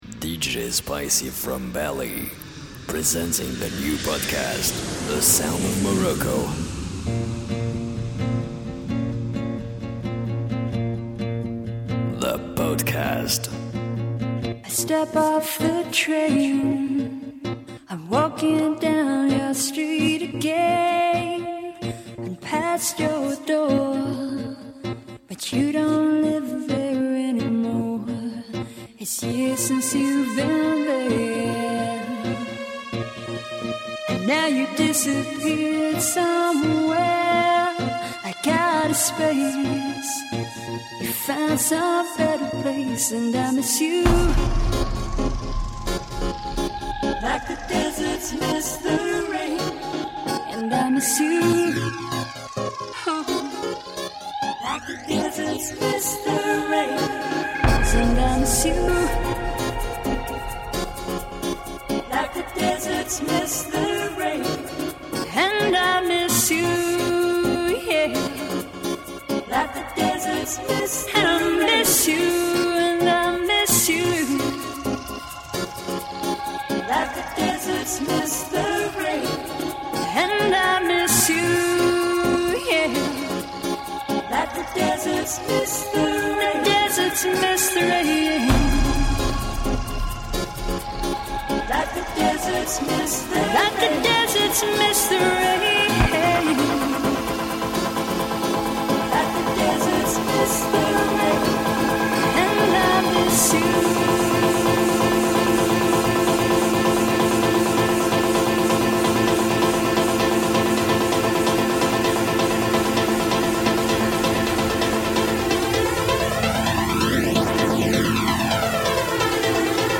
The best of electro